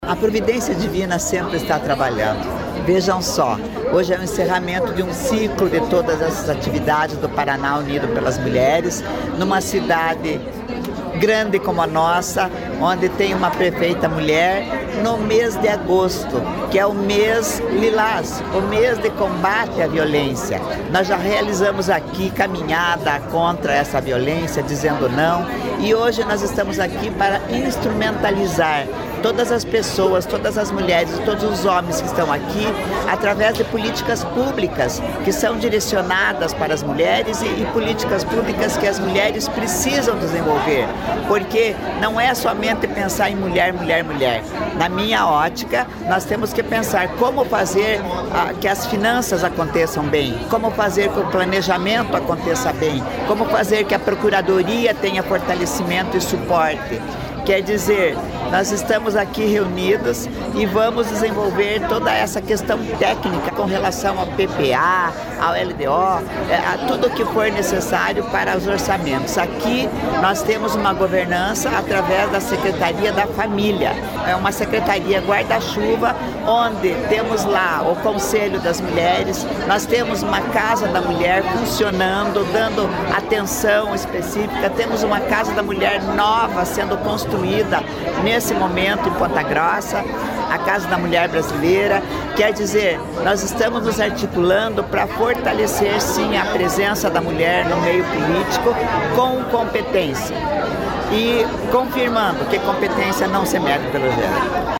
Sonora da prefeita de Ponta Grossa, Elizabeth Schmidt, sobre o fim do primeiro ciclo da Caravana Paraná Unido Pelas Mulheres | Governo do Estado do Paraná